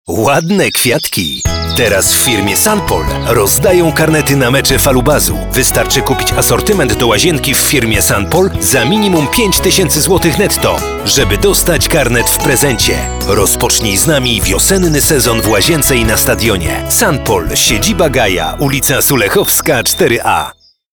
Professioneller polnischer Sprecher für TV/Rundfunk/Industrie.
Sprechprobe: Industrie (Muttersprache):